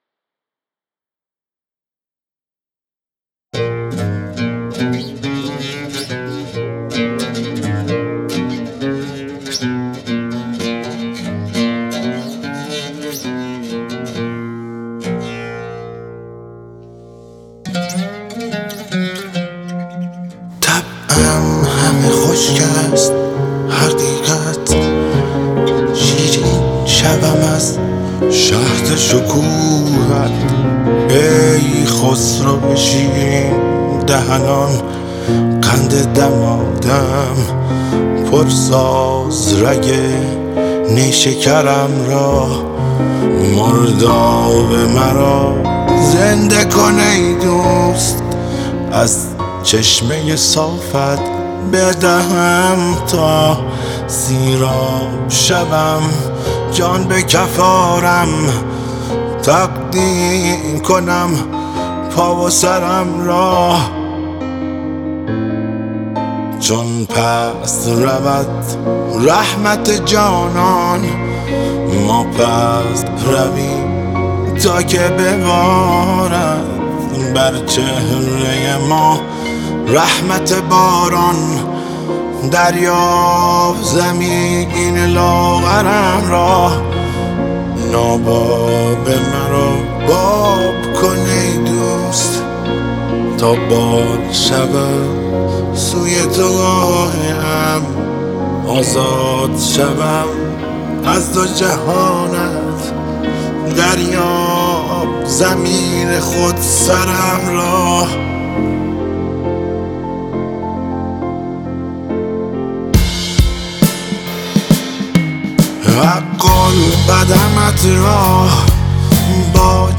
با صدای دلنشین